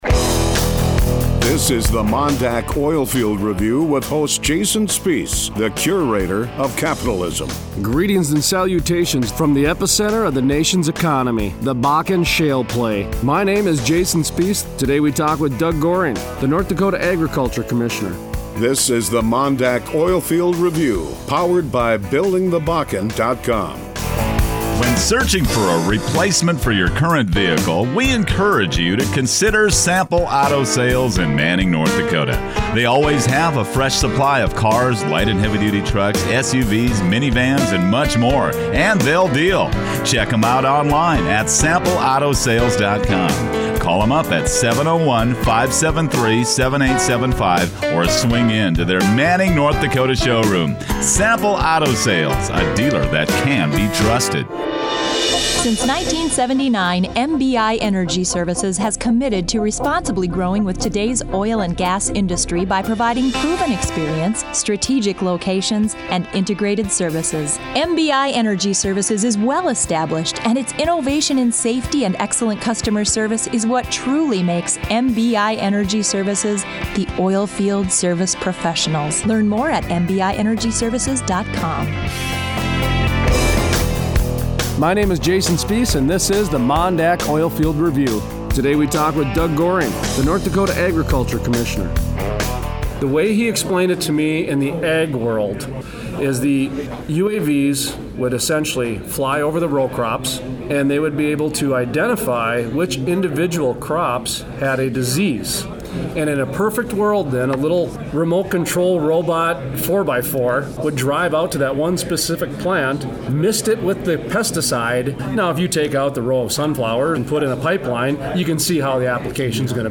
Interview: Doug Goehring, North Dakota Agriculture Commissioner